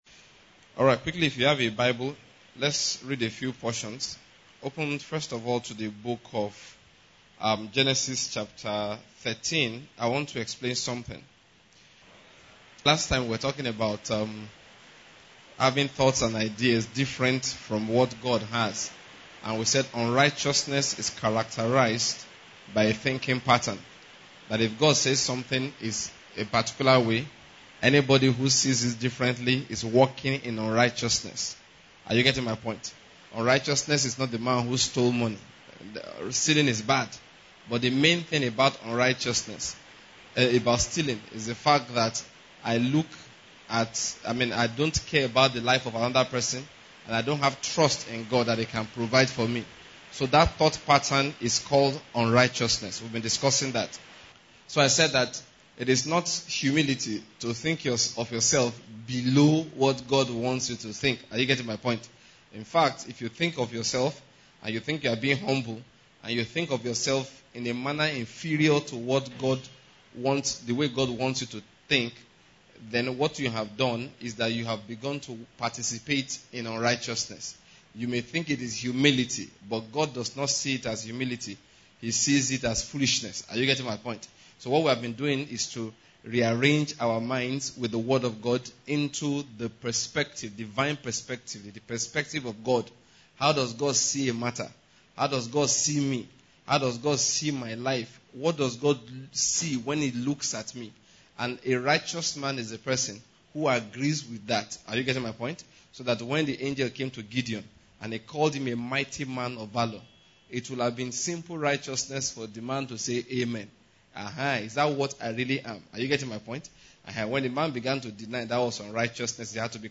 audio message